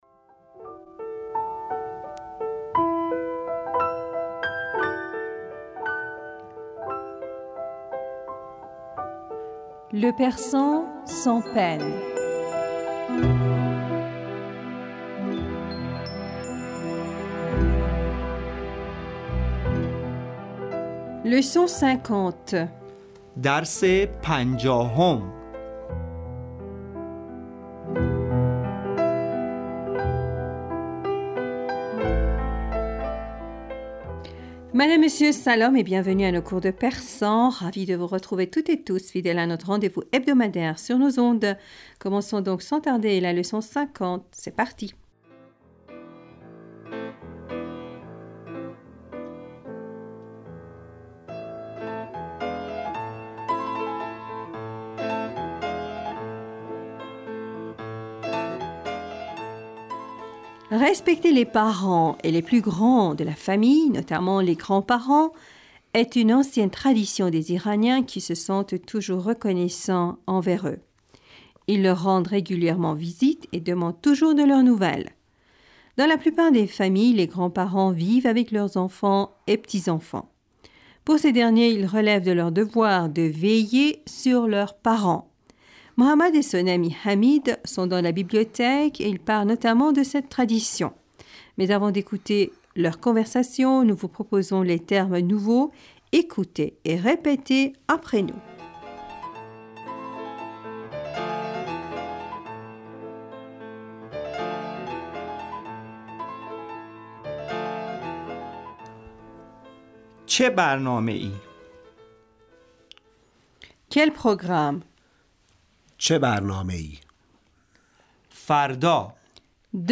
Madame, Monsieur Salam et bienvenue à nos cours de persan.
Ecoutez et répétez après nous.